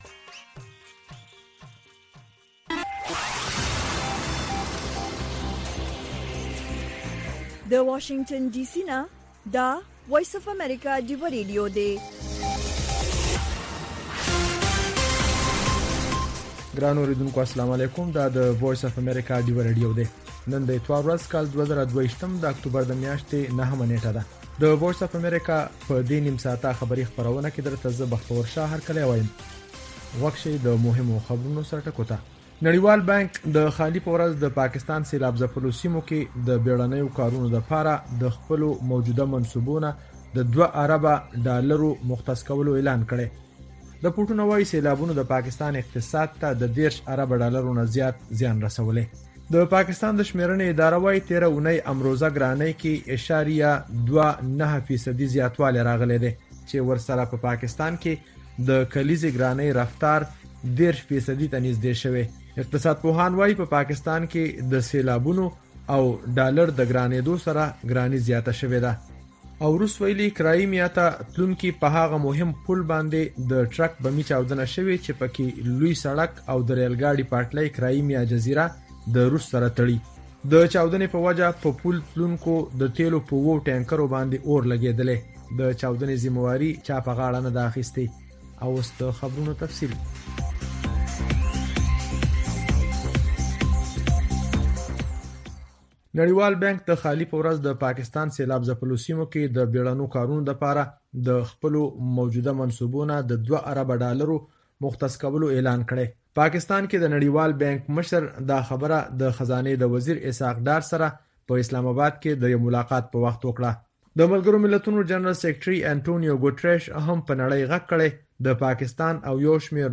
د وی او اې ډيوه راډيو سهرنې خبرونه چالان کړئ اؤ د ورځې د مهمو تازه خبرونو سرليکونه واورئ.